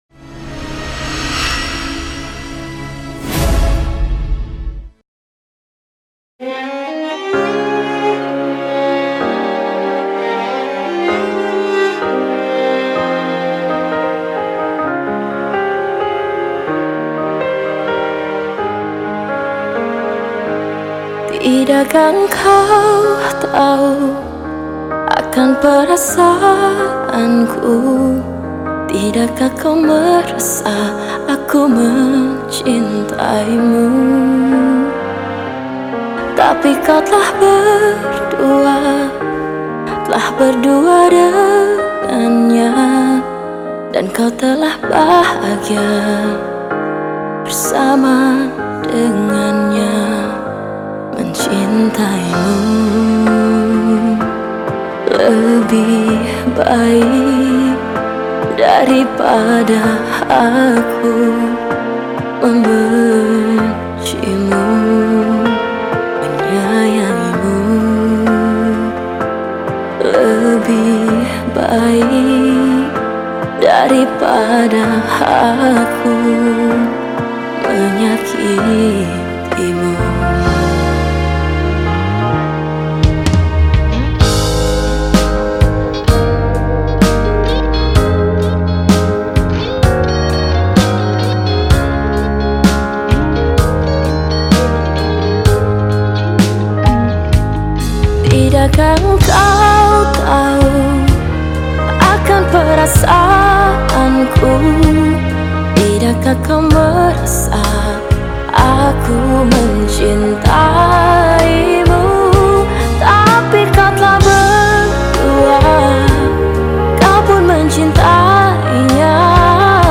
lagu dangdut